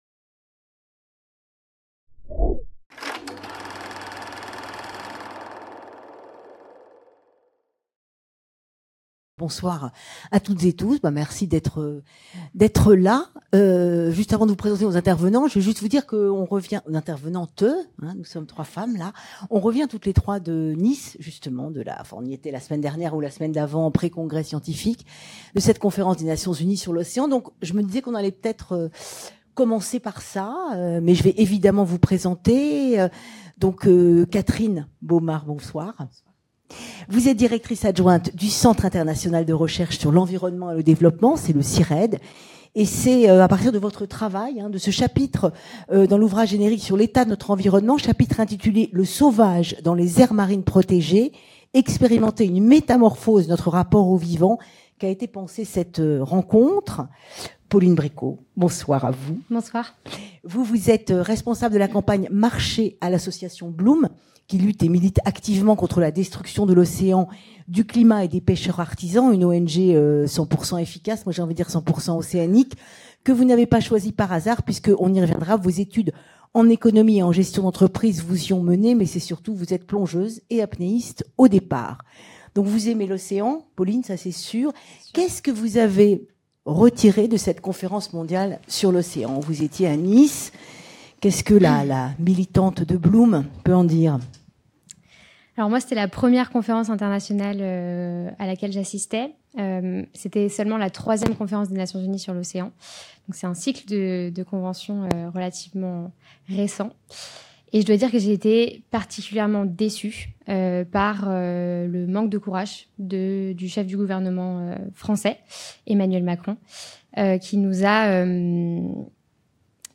au Forum de la FMSH